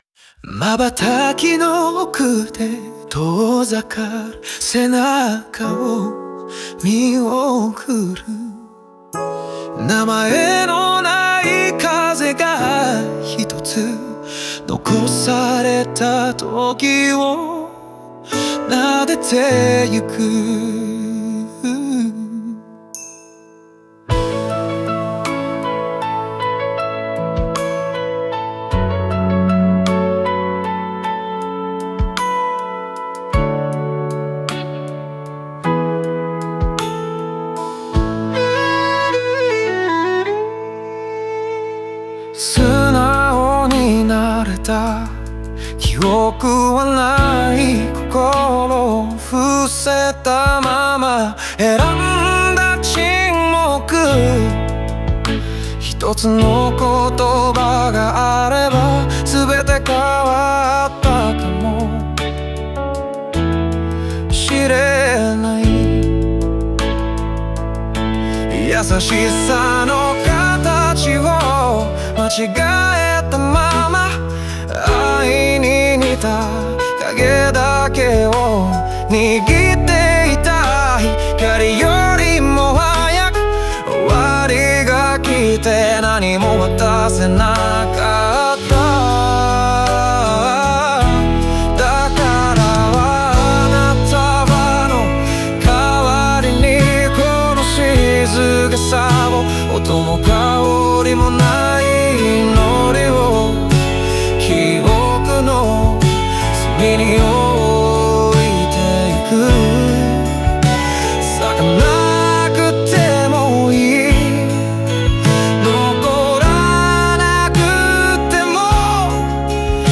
音楽とともに内面の葛藤を浮かび上がらせ、終わりに向けて解放感と受容が感じられる構成になっています。